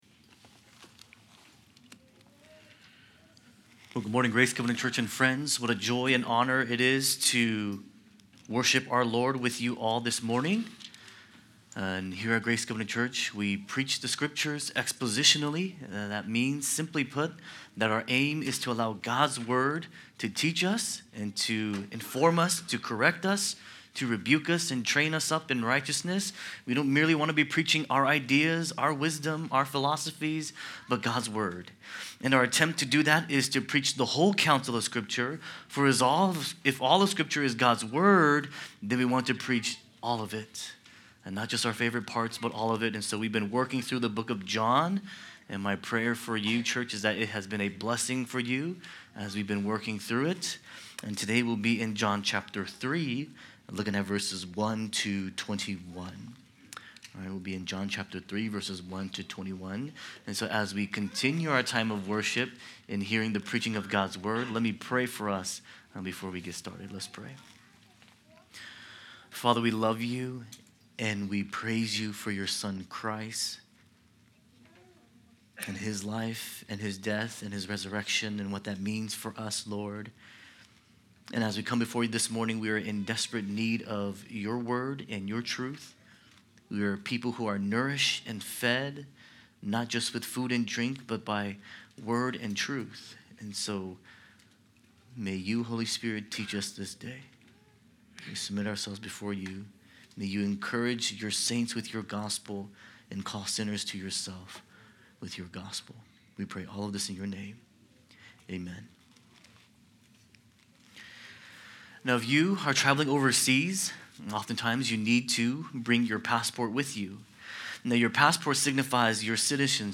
Sermons | Grace Covenant Church